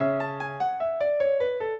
piano
minuet5-8.wav